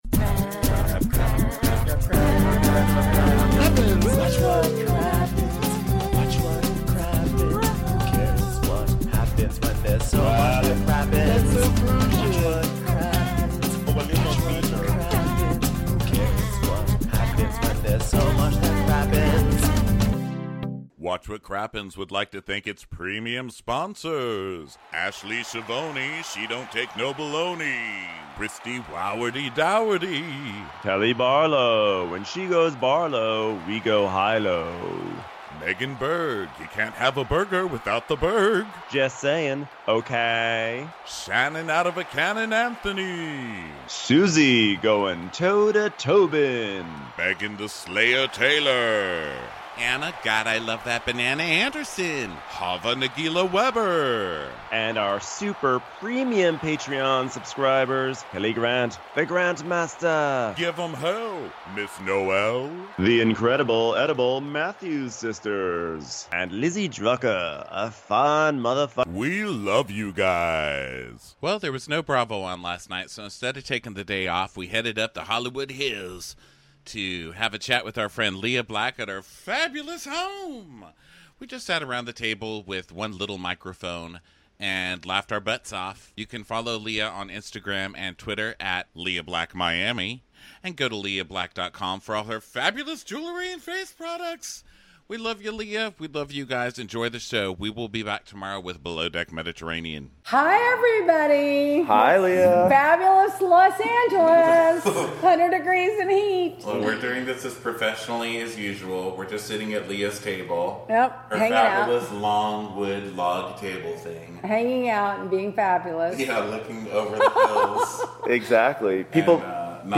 We had another Bravo-less night last nigh, so for today's show we made a field trip to the gorgeous home of the fabulous Lea Black from Real Housewives of Miami and Flipping Out.